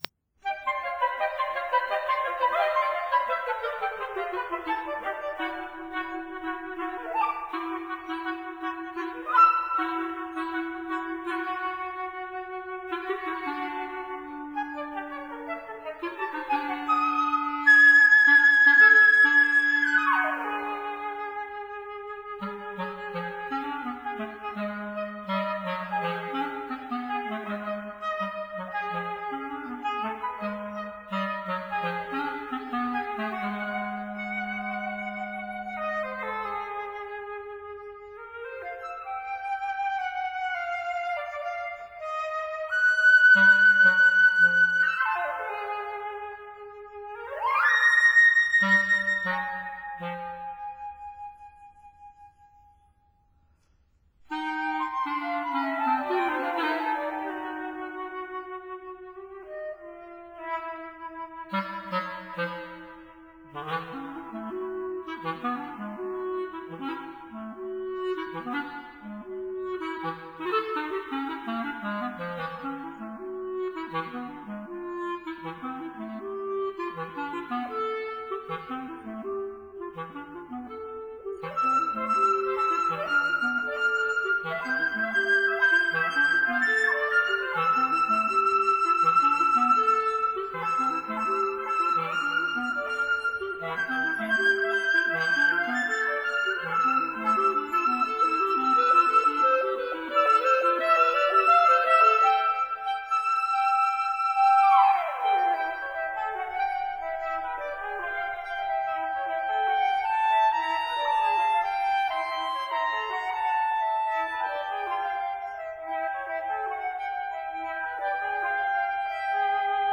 flute
oboe
cor anglais
clarinet
bassoon
cello
guitar